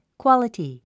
発音
kwɑ’ləti　クオラティ
クオラティと発音しましょう。